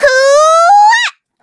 Taily-Vox_Casting2.wav